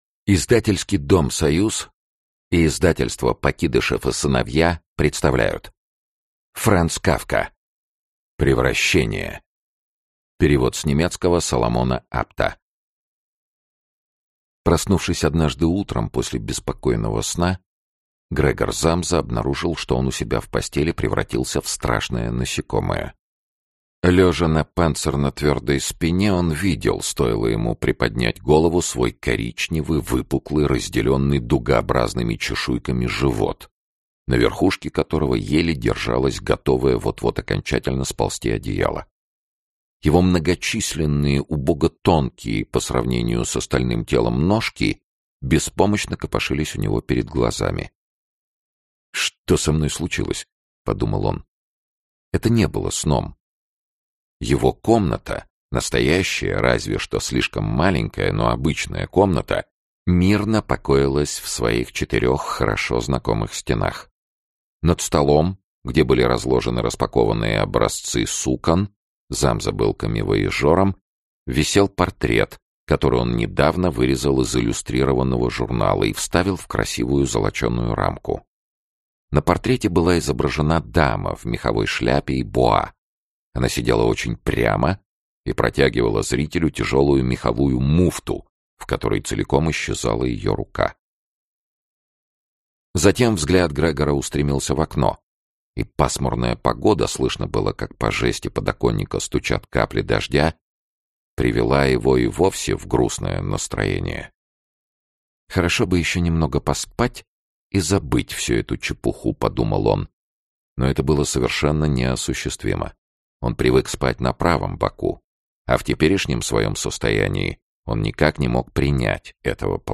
Aудиокнига Превращение Автор Франц Кафка Читает аудиокнигу Сергей Чонишвили.